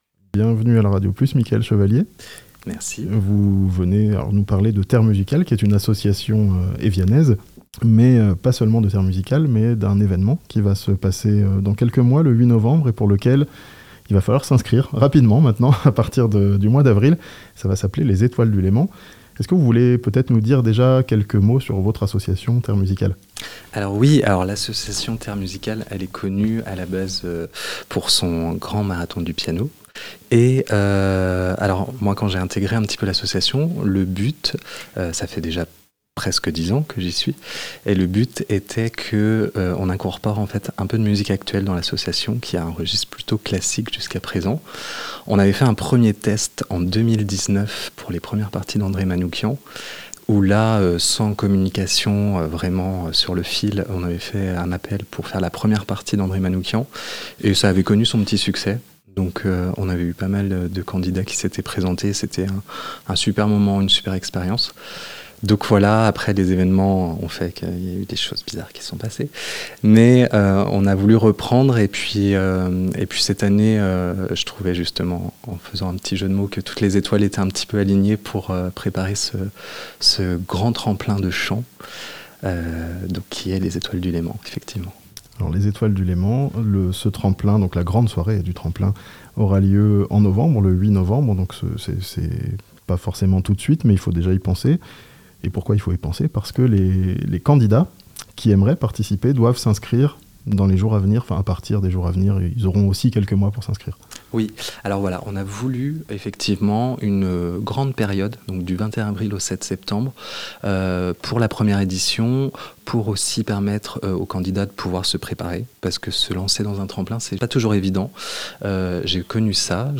Les inscriptions sont ouvertes pour le tremplin de chant "Les étoiles du Léman" (interview)